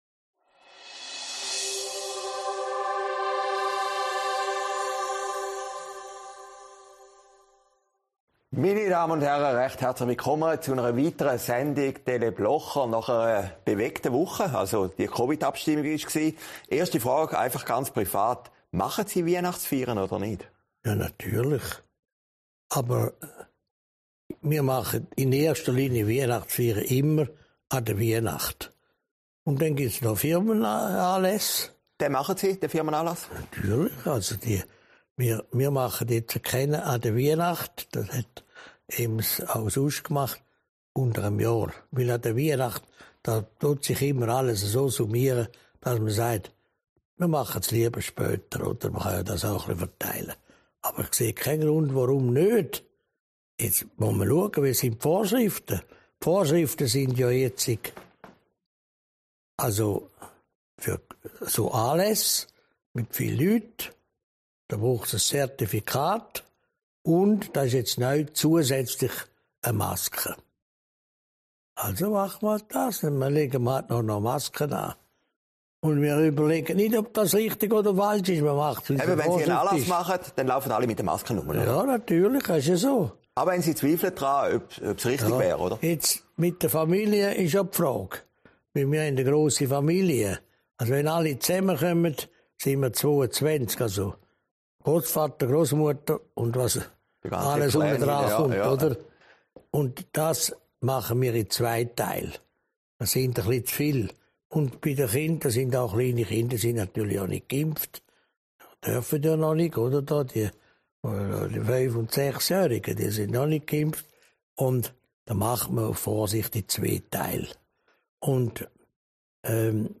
Dezember 2021, aufgezeichnet in Herrliberg